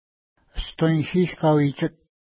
Pronunciation: əstwejʃi:ʃ ka:wi:tʃət
Pronunciation